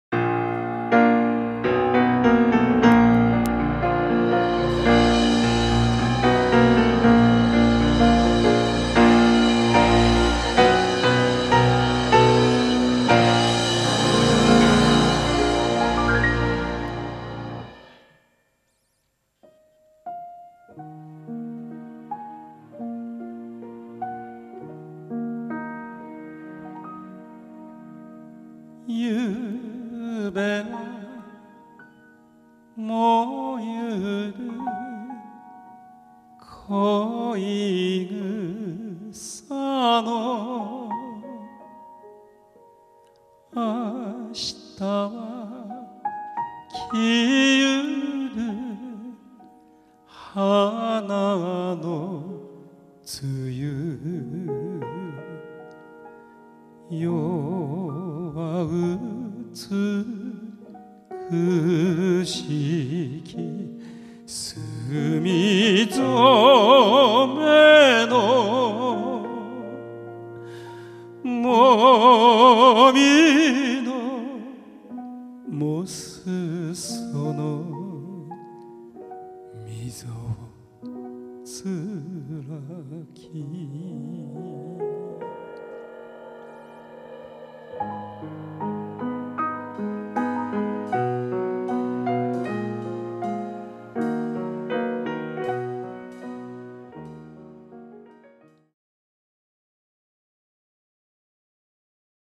☆ライブ録音が試聴できます。